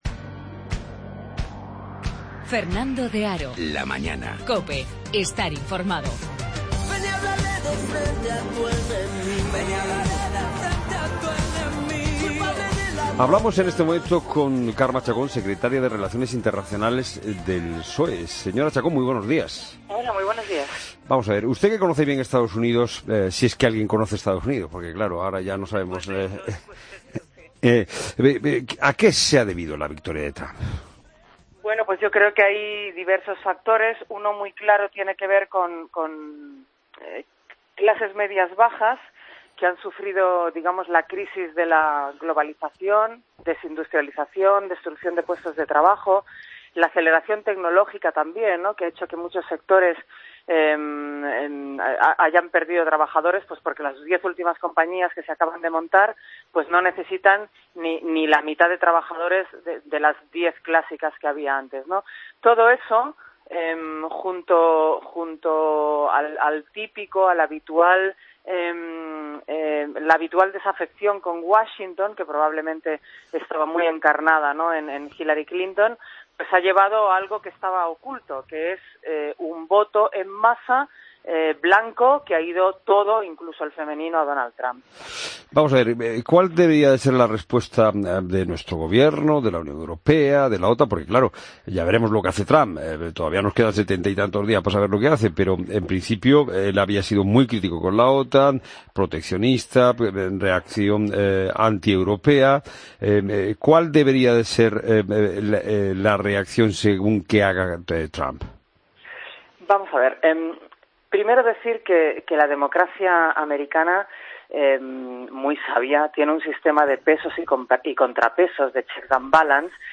Entrevista Carme Chacón en 'La Mañana Fin de semana'